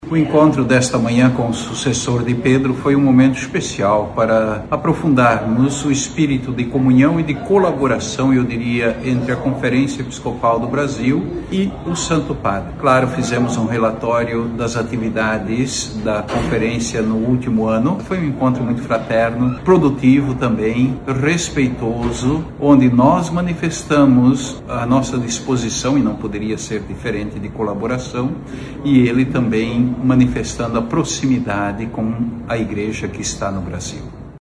SONORA-1-DOM-JAIME-1.mp3